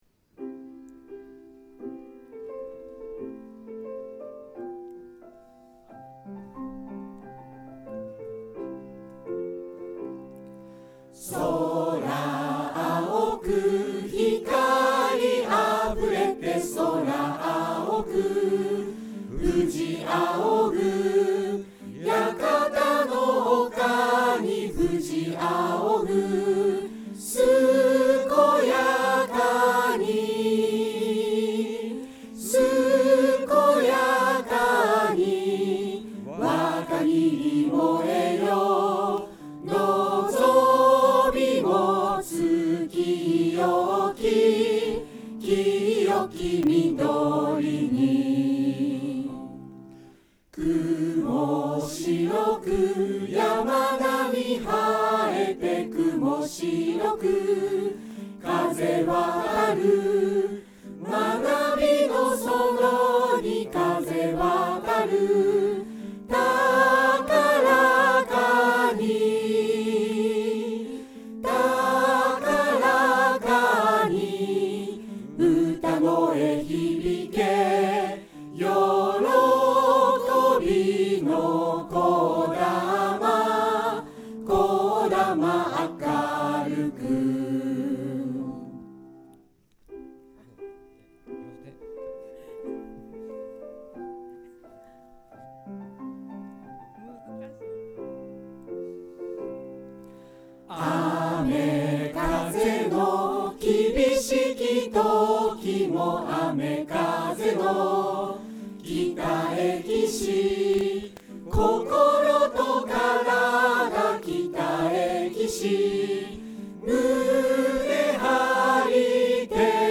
▶先生たちの校歌・音のみ